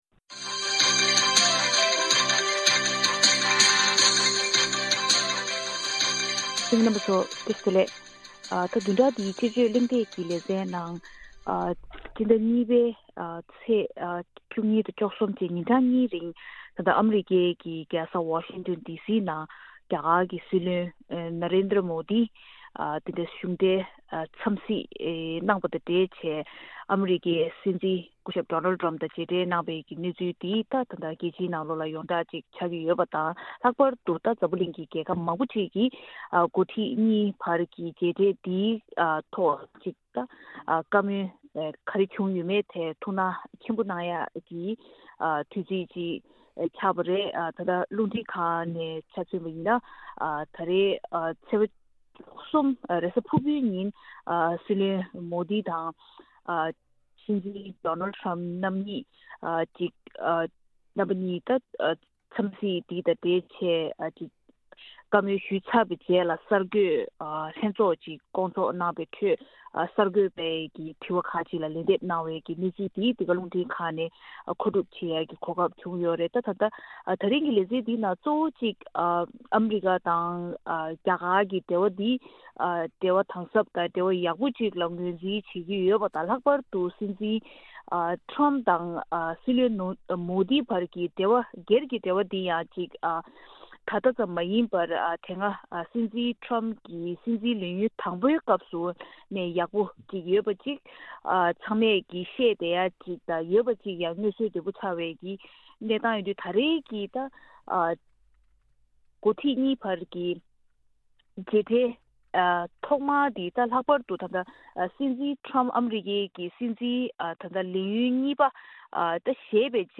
དཔྱད་གཞིའི་གླེང་མོལ་ཞུས་བ་ཞིབ་ཕྲ་གསན་རོགས།